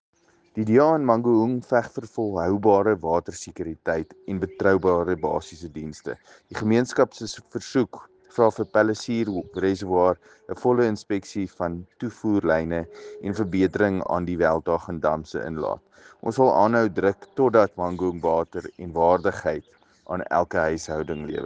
Afrikaans soundbites by Cllr Paul Kotzé and